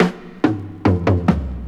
Sick Fill.wav